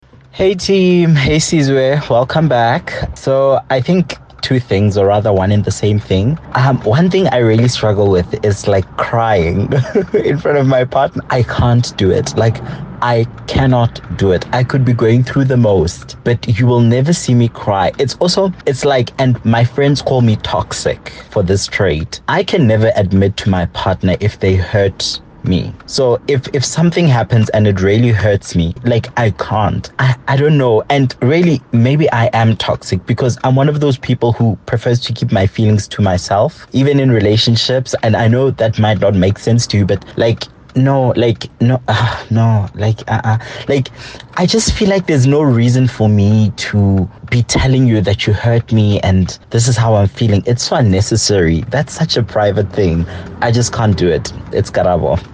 Listen to Kaya Drive listeners sharing their boundaries: